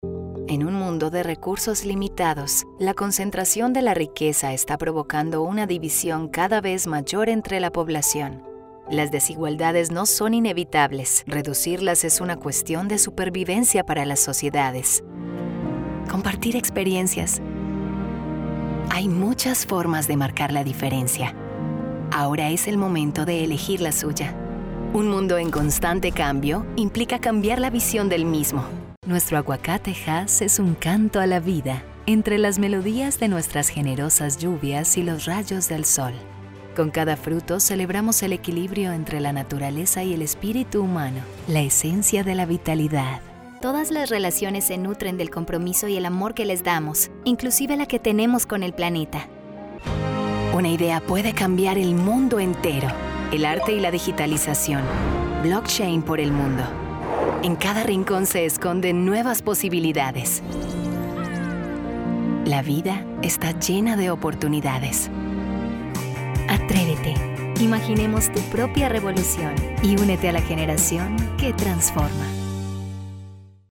Espanhol (latino-americano)
Vídeos Explicativos
Cabine de gravação profissional isolada